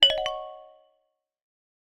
beep1.mp3